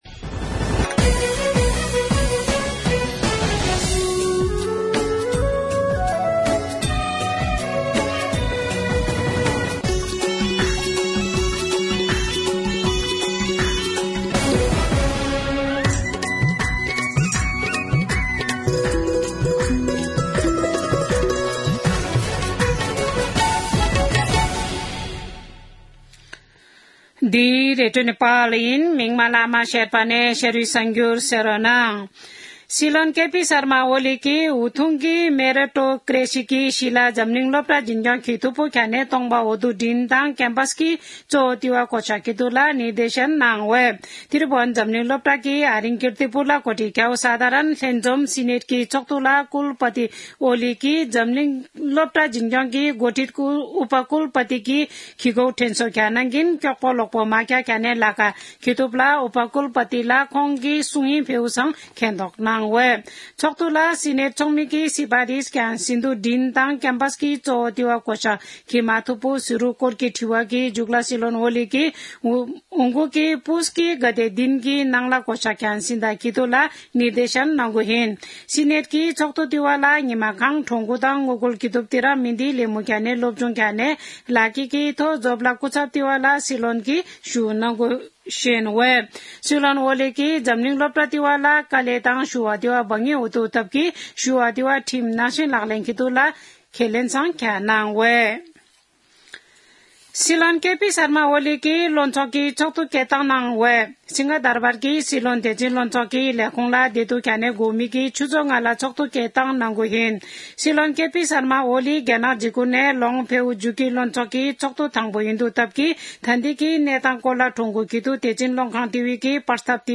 शेर्पा भाषाको समाचार : २८ मंसिर , २०८१
Sherpa-news.mp3